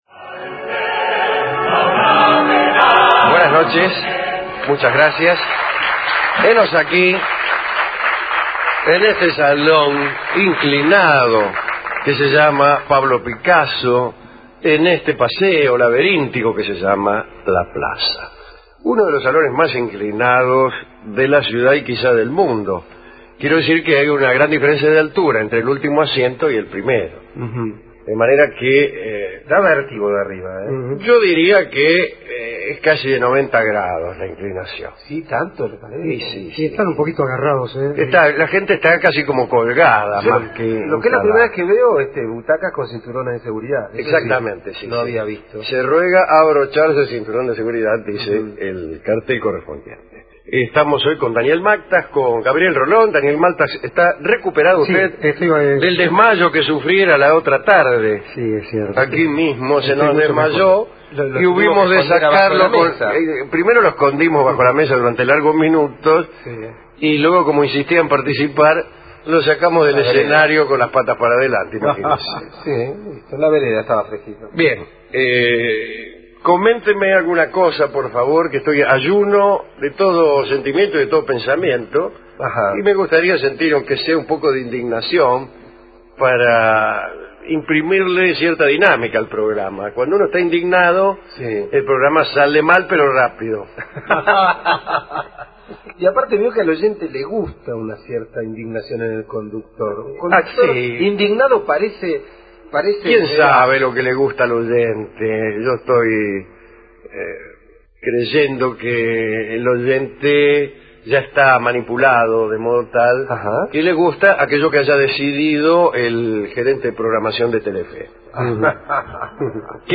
Paseo La Plaza, Sala Pablo Picasso, Buenos Aires